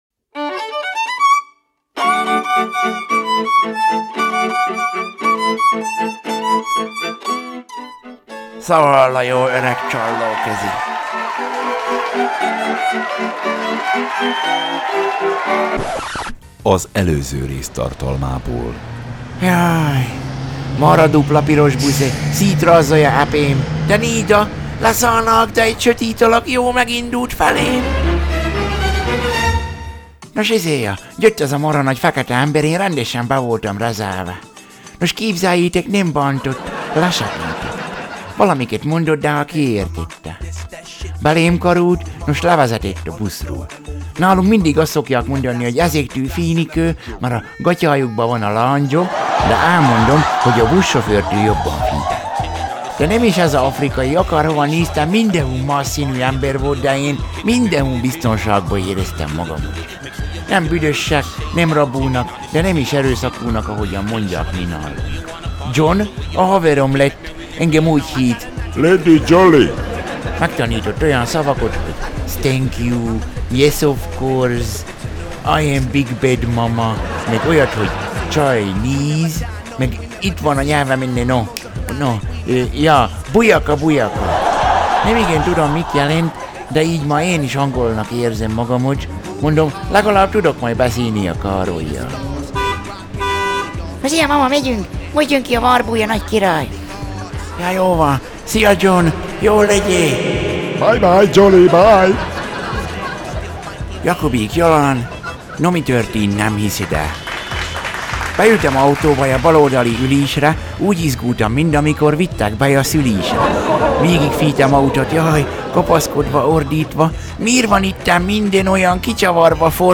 The British Grenadiers fife and drum